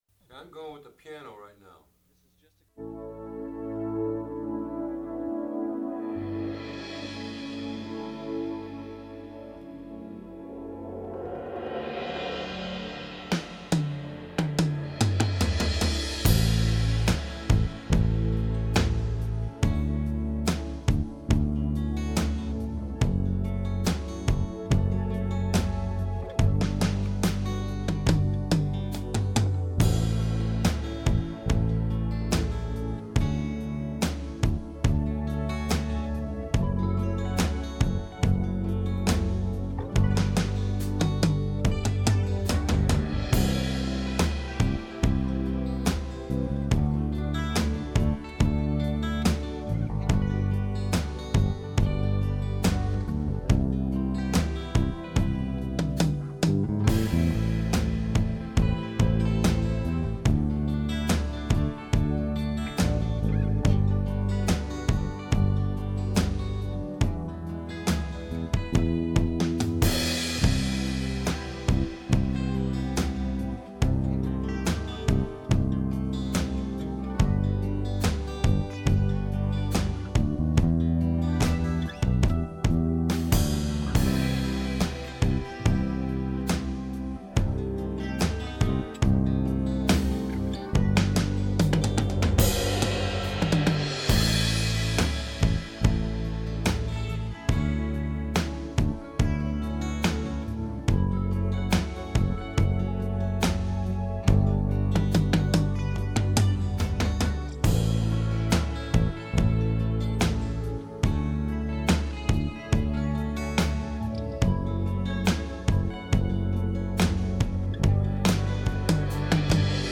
(without sax solo)